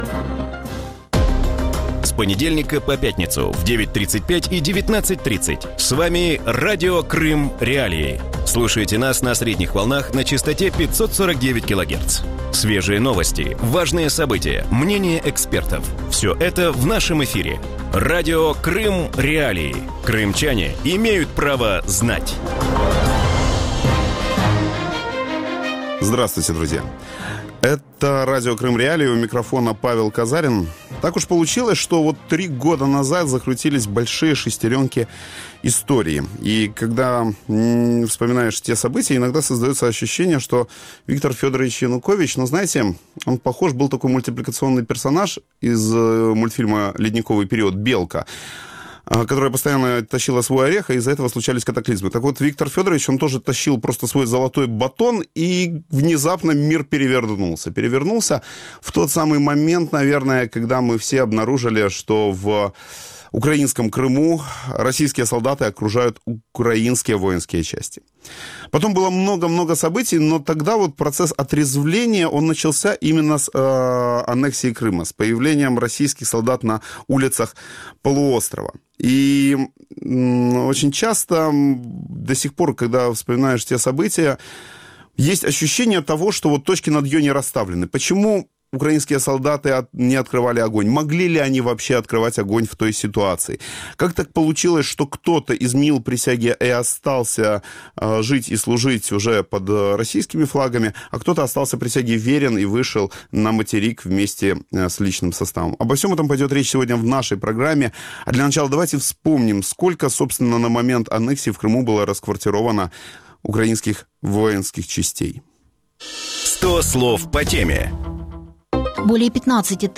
В эфире Радио Крым.Реалии говорят о ситуации с украинскими военными на полуострове во время российской аннексии. Какие команды из Киева получали командиры крымских частей, как действовали российские солдаты и был ли шанс «отбить» Крым?